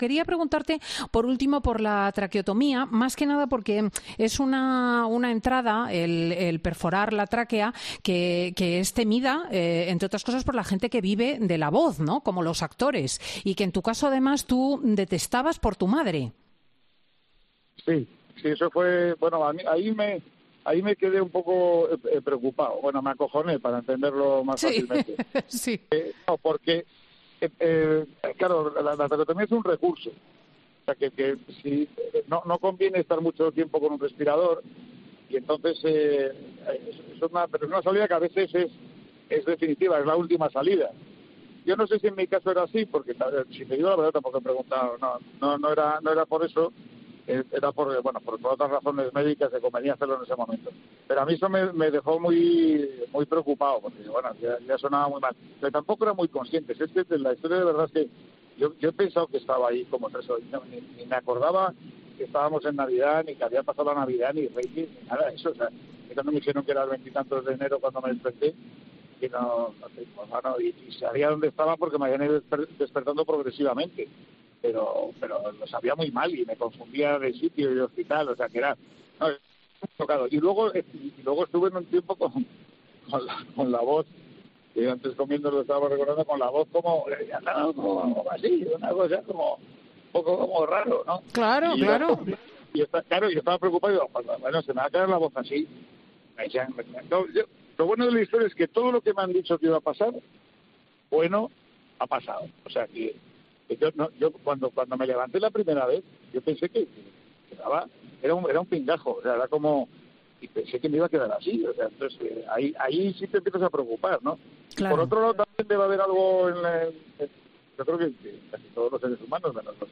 El intérprete se refirió concretamente a una de las consecuencias directas en su físico por padecer una enfermedad esencialmente respiratoria, además de explicar las alucinaciones que sufrió al despertarse en el hospital que puedes recordar en esta entrevista.